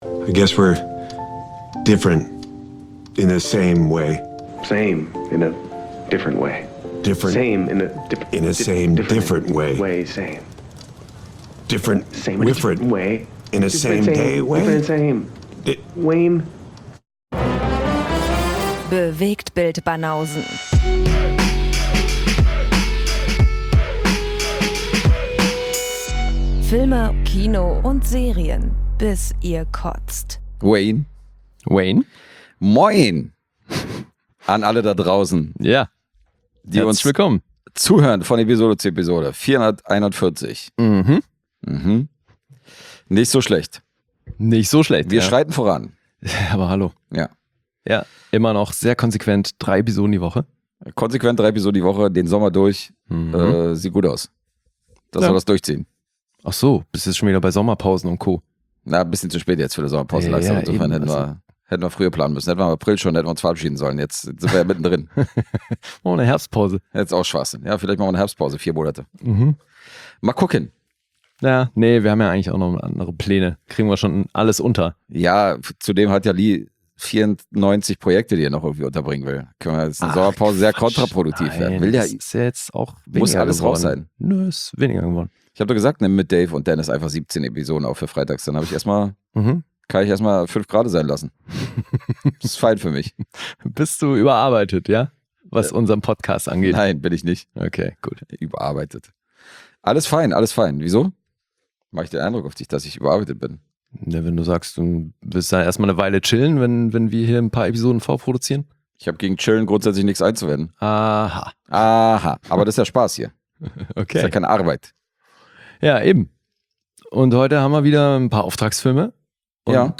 Zwei Dudes - manchmal mit Gästen - quatschen über Gesehenes aus Kino, Homekino und Streaming-Plattformen und punkten zudem mit gefährlichem Halbwissen.